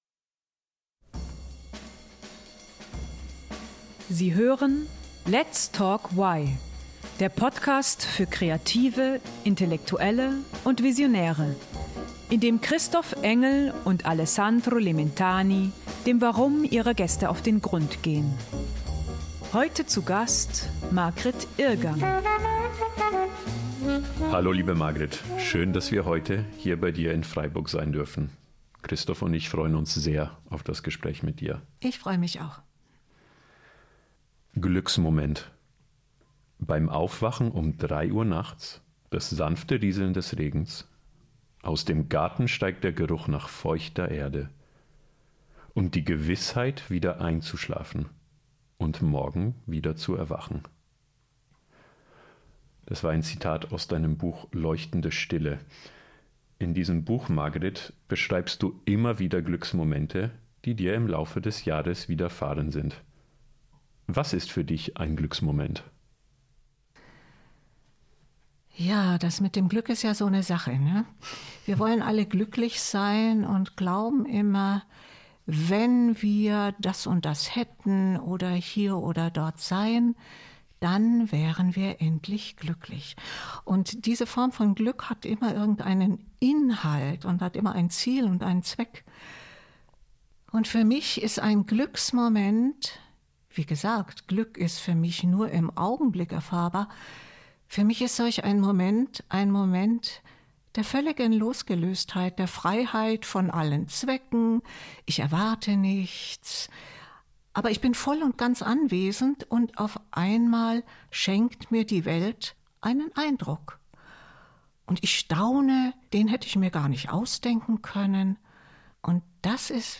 Erfahrt in dieser Folge, was ein Glücksmoment ist, warum Zen-Praxis und künstlerische Schaffenskraft zusammenhängen und wie Achtsamkeit das eigene Leben in ein Kunstwerk verwandeln kann. Das Interview wurde am 31. August 2024 aufgezeichnet.